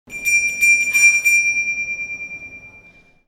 Polar Express Christmas Bell
PolarExpress-ChristmasBell .mp3